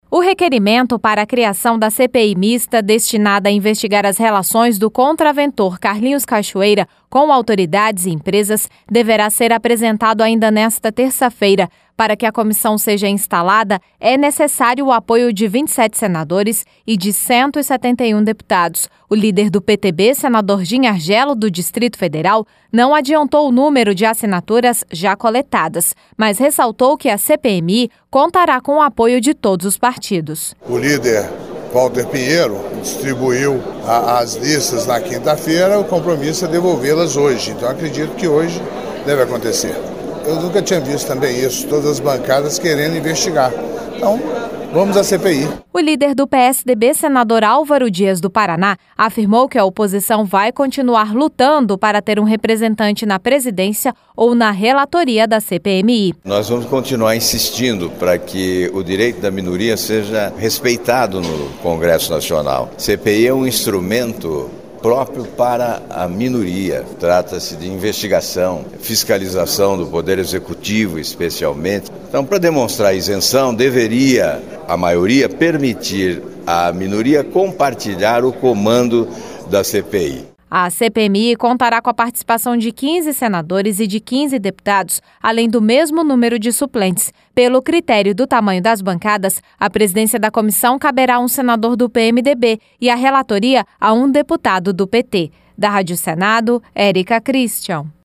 REP: O líder do PSDB, senador Álvaro Dias, do Paraná, afirmou que a oposição vai continuar lutando para ter um representante na presidência ou na relatoria da CPMI.